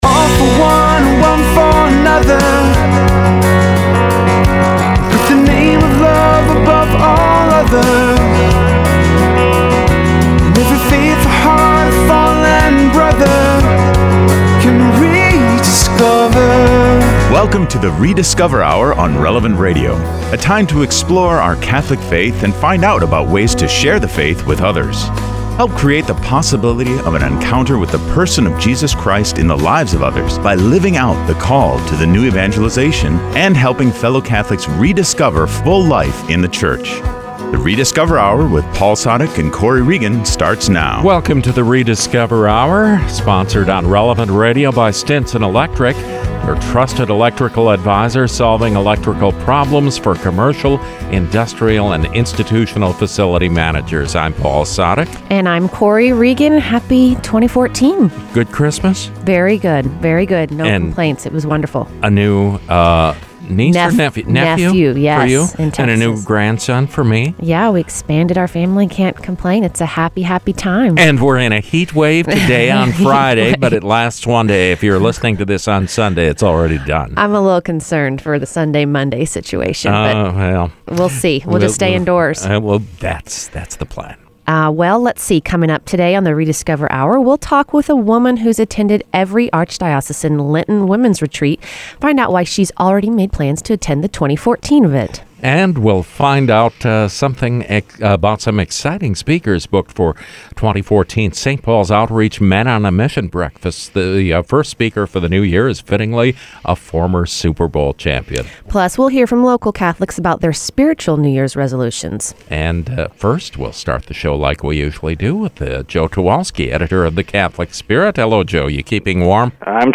Hablamos con una mujer que ha asistido a todos los retiros de Cuaresma para mujeres patrocinados por la Oficina de Matrimonio, Familia y Vida.